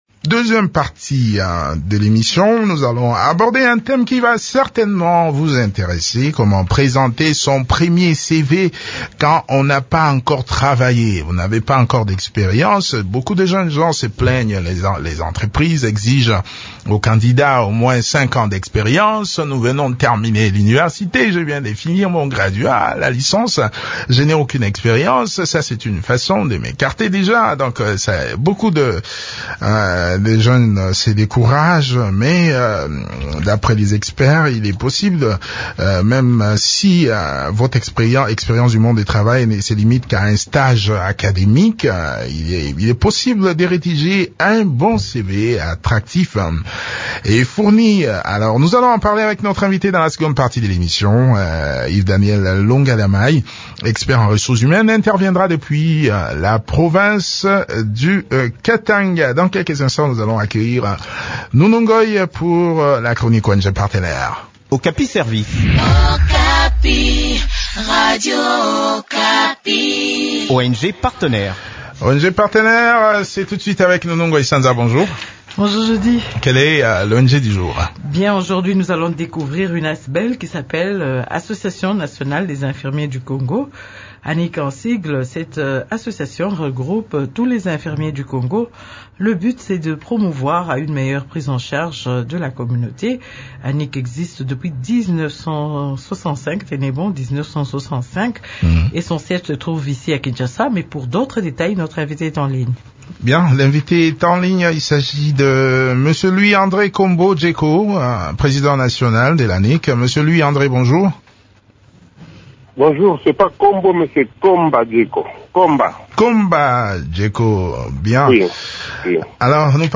spécialiste en ressources humaines.